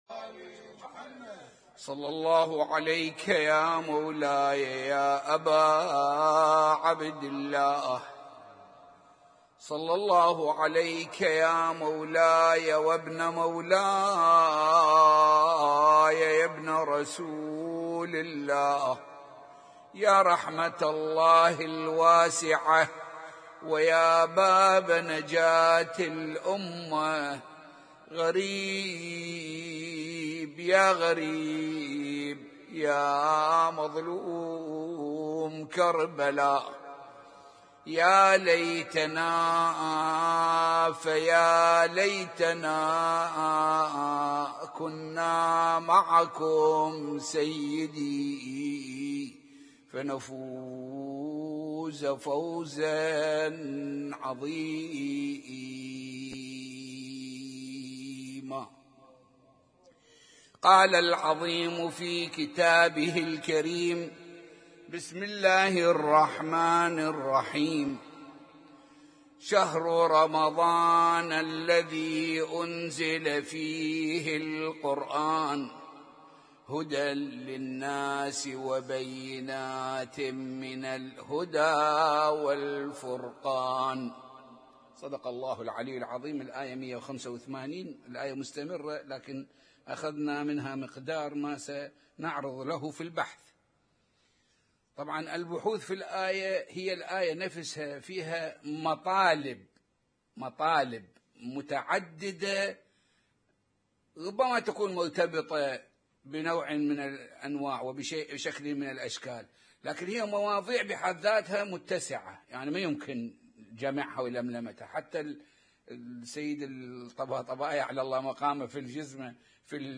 اسم النشيد:: محاضرة بعنوان ومدارج بلوغ السعادة